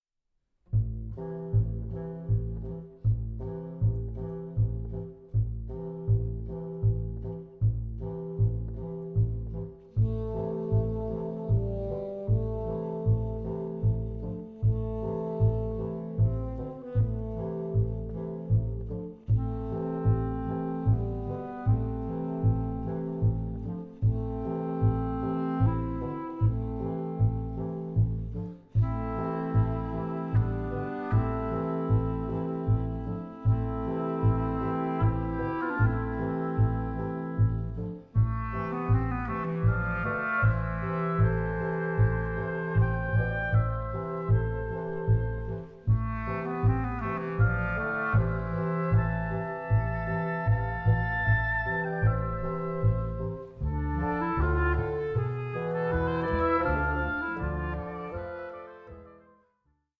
the versatile reed players
the reed quintet.